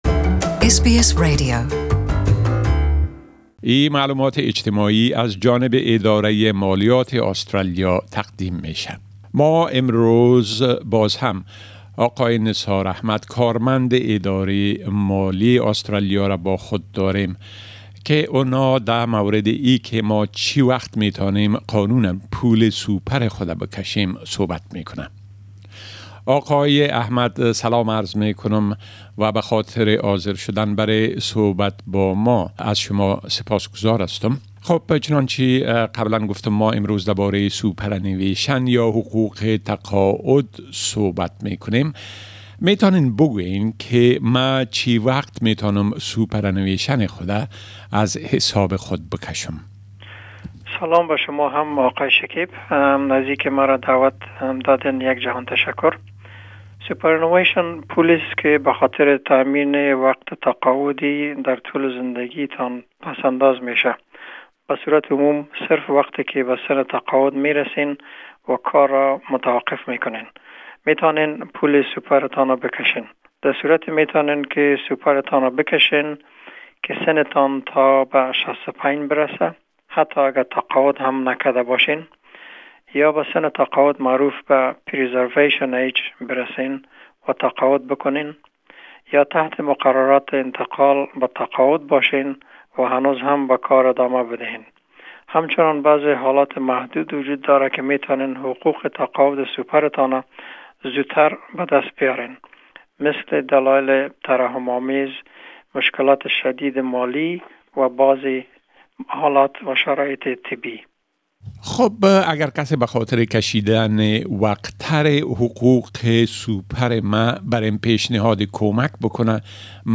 The following tax talk segment is a community service announcement from the Australian Taxation Office.